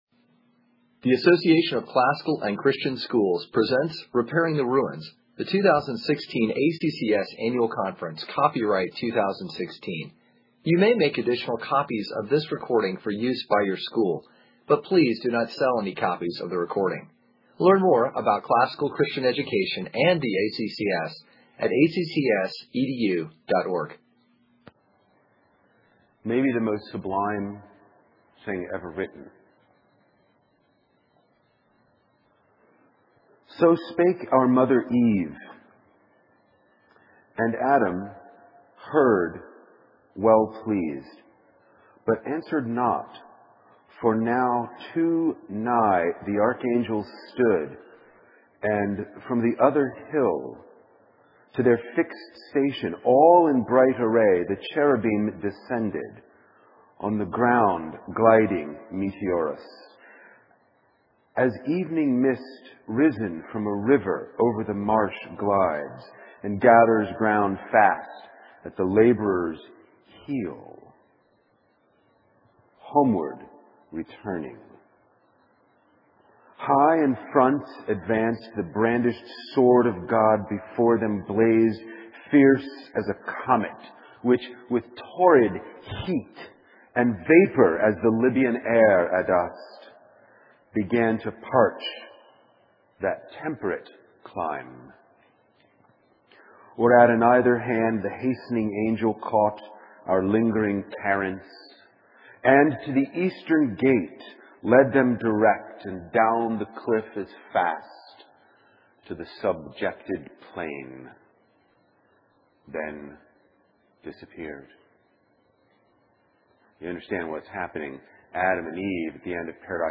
2016 Workshop Talk | 1:02:10 | All Grade Levels, General Classroom